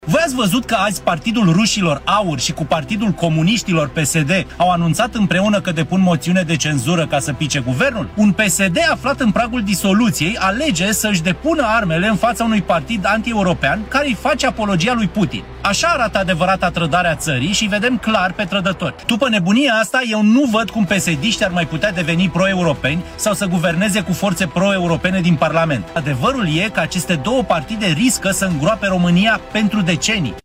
Deputatul USR, Alexandru Dimitriu: „Așa arată adevărata trădare a țării”